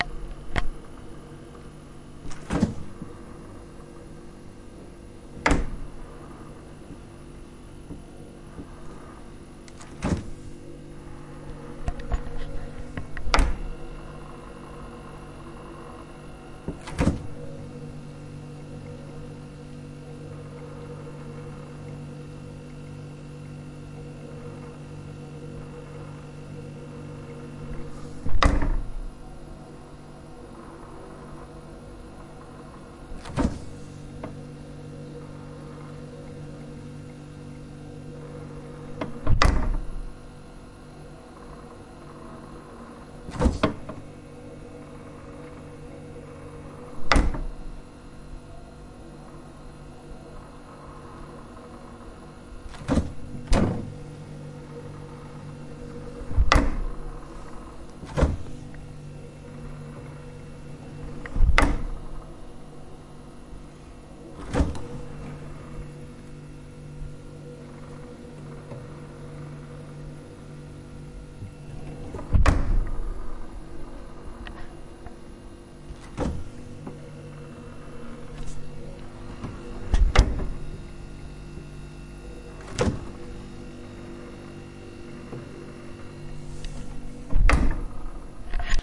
声音 " 旧冰箱
描述：旧冰箱振动电机的声音。
Tag: 振动 马达 冰箱 嘎嘎